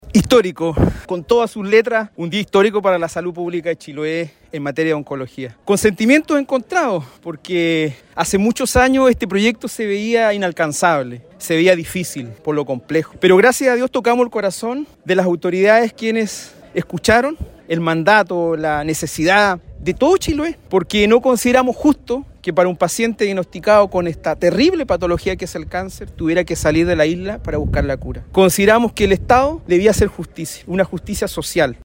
El consejero por la provincia de Chiloé, Francisco Cárcamo, enfatizó el rol del Gobierno Regional en la financiación del proyecto.